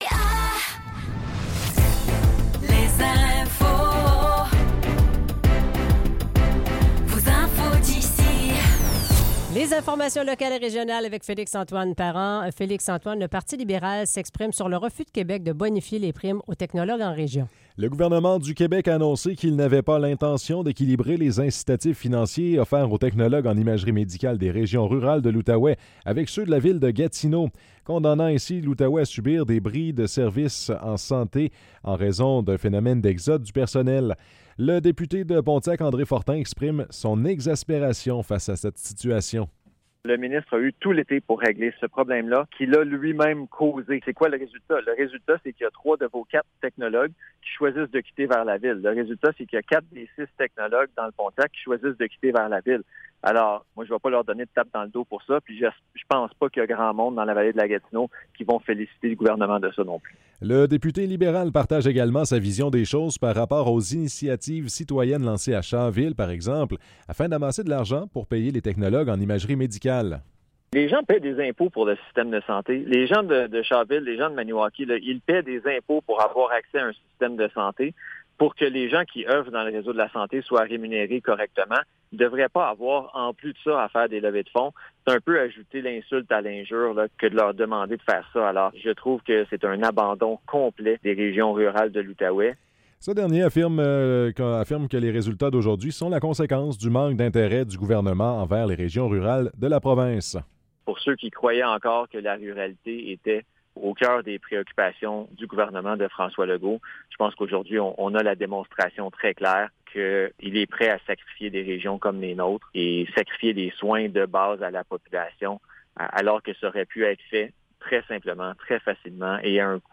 Nouvelles locales - 6 septembre 2024 - 15 h